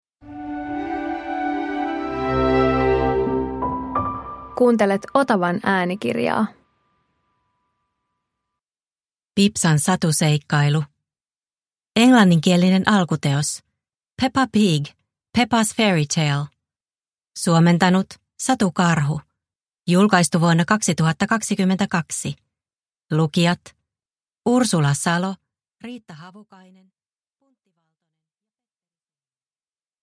Pipsan satuseikkailu – Ljudbok – Laddas ner